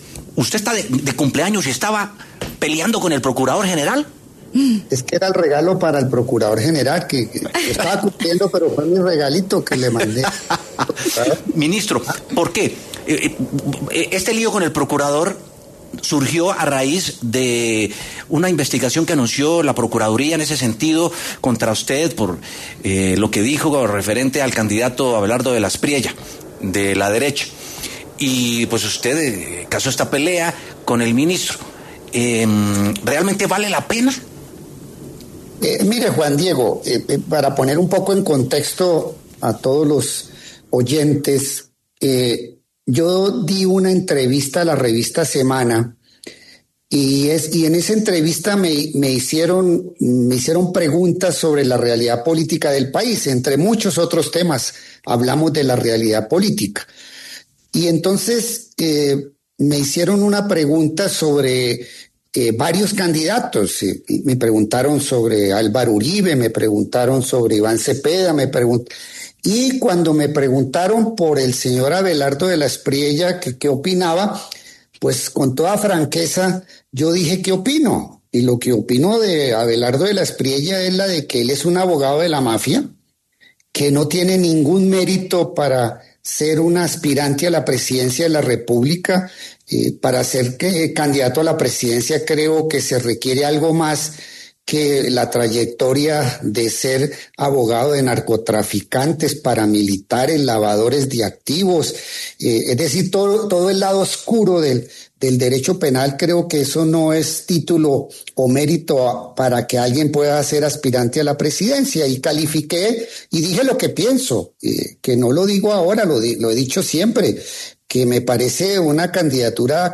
Montealegre habla en W Sin Carreta sobre Gregorio Eljach y Abelardo de la Espriella